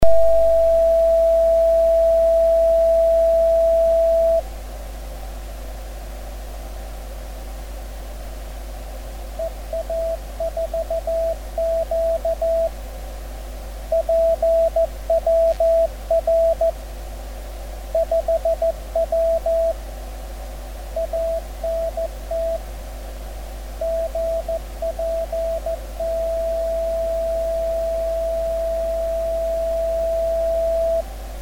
Die hier aufgeführten Stationen wurden von mir selbst empfangen.